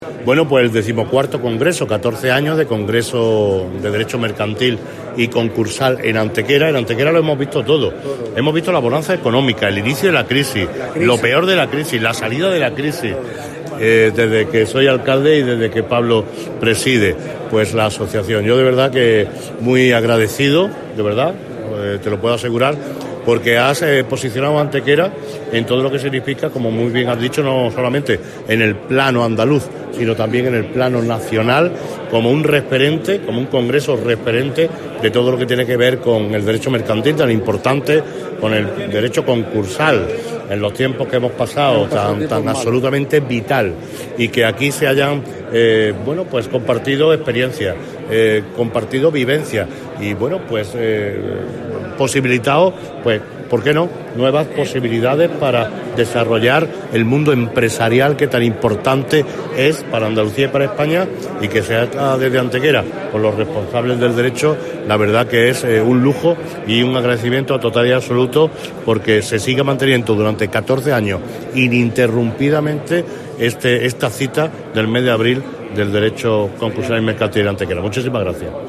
El Alcalde de Antequera preside la apertura oficial del XIV Congreso de Derecho Mercantil y Concursal de Andalucía que se desarrolla hasta el próximo sábado en nuestra ciudad
Cortes de voz M. Barón 537.96 kb Formato: mp3